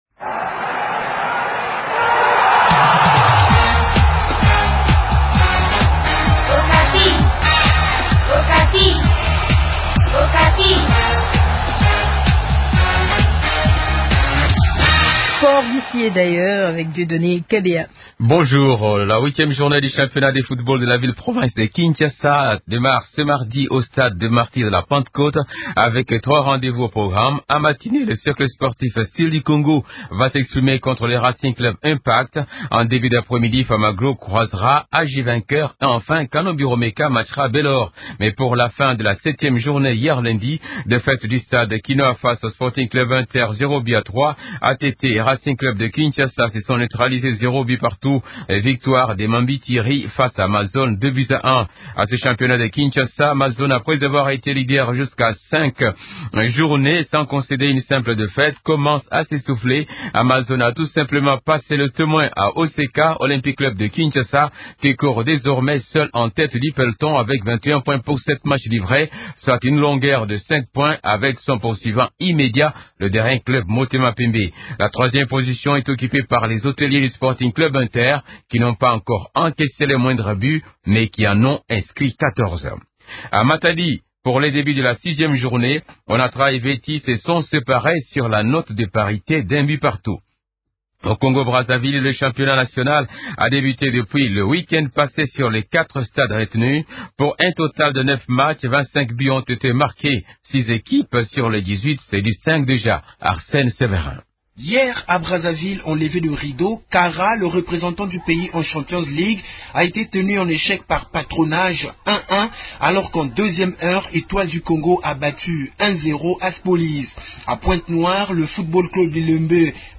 interviewé